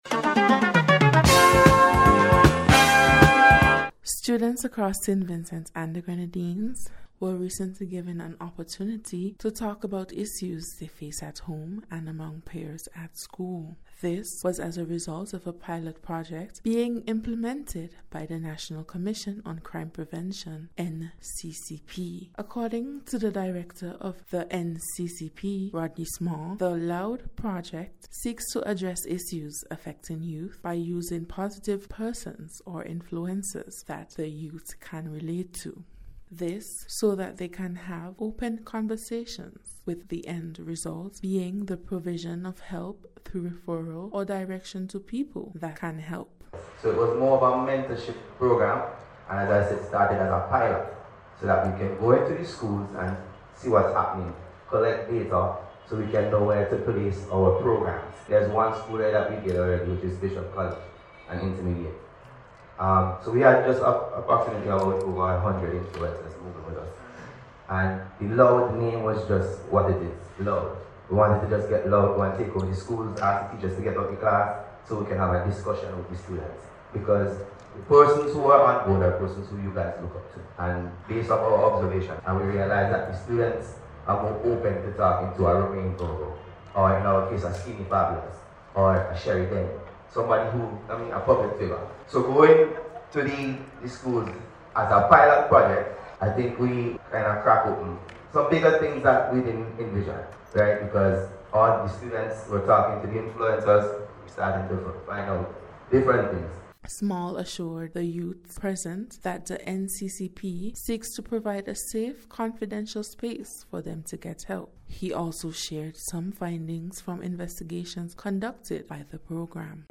NBC’s Special Report- Friday 8th November,2024